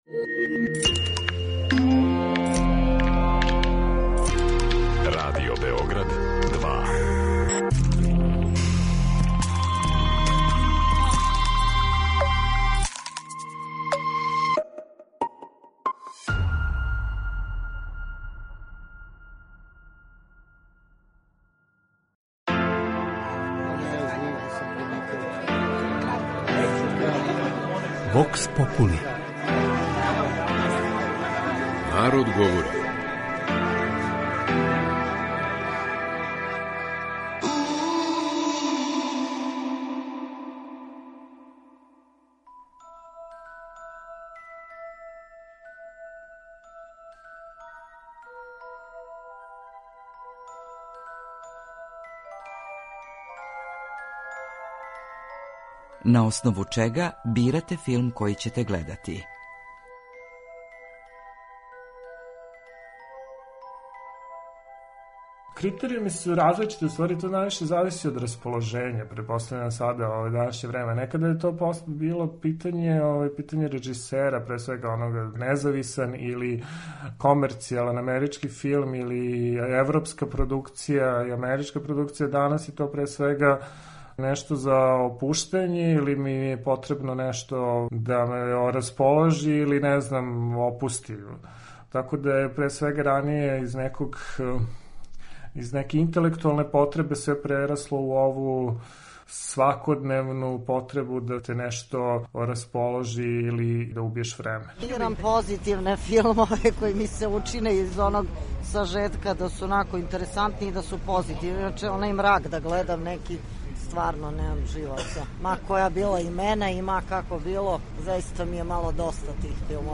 Вокс попули